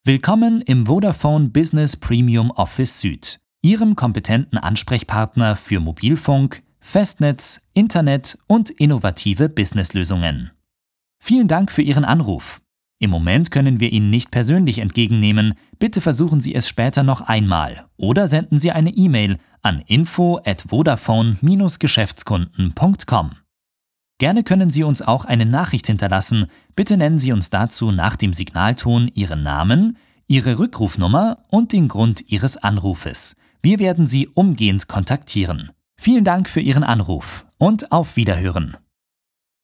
Sprecher Telefonansage | Vodafone